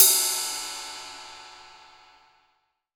Index of /90_sSampleCDs/AKAI S6000 CD-ROM - Volume 3/Ride_Cymbal1/18INCH_ZIL_RIDE